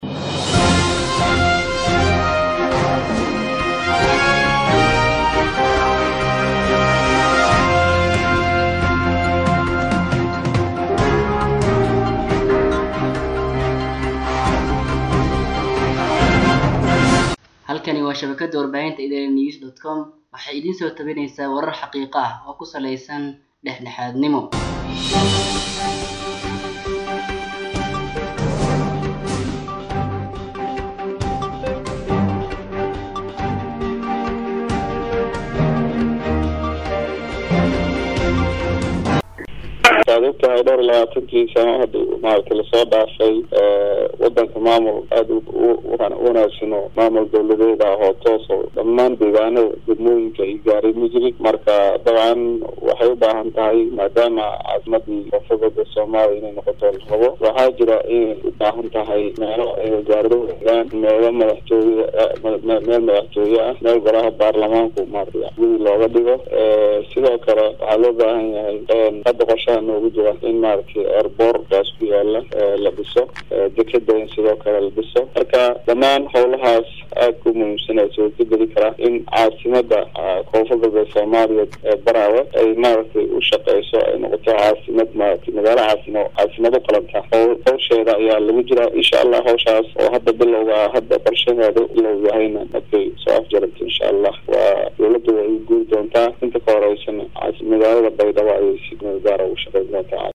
Baydhobo (INO) — Wasiirka Arimaha Gudaha Maamulka Koonfur Galbeed oo la Hadlay Warbaahinta ayaa Waxaa uu sheegay in ay Dhawaan Magaalada Baraawe oo Xarun u Noqon Doonta Maamulka Koonfur Galbeed Soomaaliya ay ka Bilaabi Doonan Dhismaha Garoon Diyaaradeed.